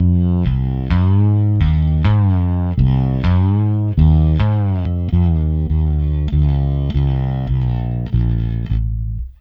Ala Brzl 1 Fnky Bass-F.wav